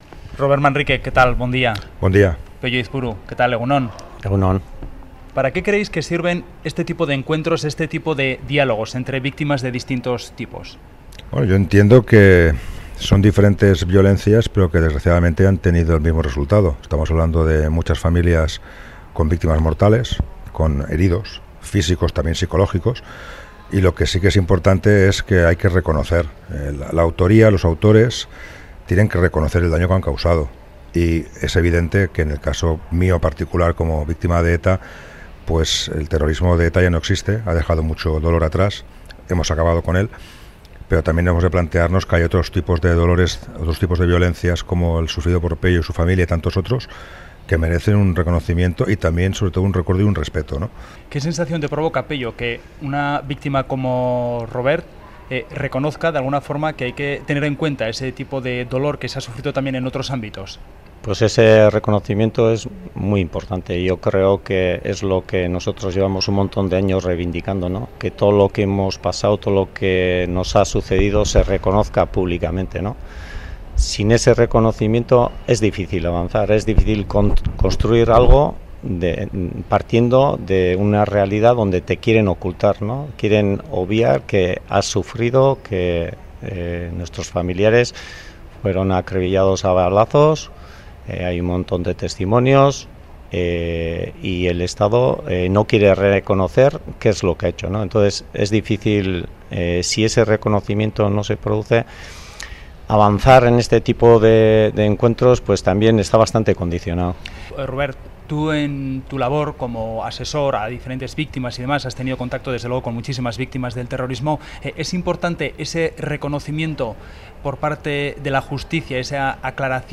Radio Euskadi CRÓNICA DEL FIN DE SEMANA 'Solo se presta atención a las víctimas mediáticas' Publicado: 01/12/2019 09:00 (UTC+1) Última actualización: 01/12/2019 09:00 (UTC+1) Una víctima de ETA, y una de abusos policiales dialogan en Crónica de Euskadi-Fin de semana sobre reconocimiento, dolor y justicia.